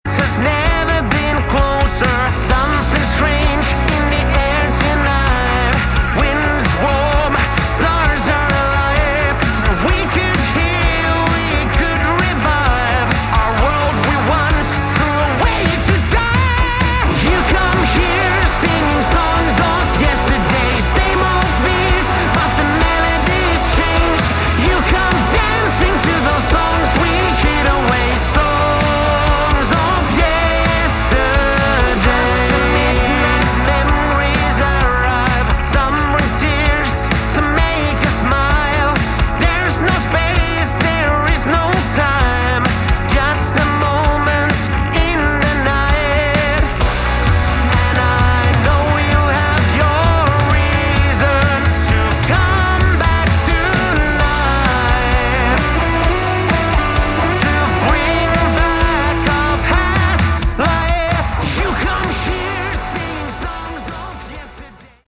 Category: Melodic Rock
vocals
guitars
drums
bass